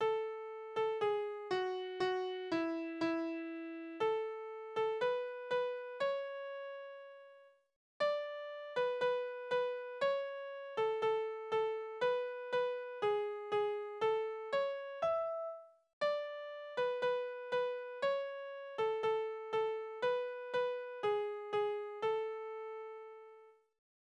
Dialoglieder: Hans de stund wol fe de Dör
Tonart: A-Dur
Taktart: 4/4
Tonumfang: Oktave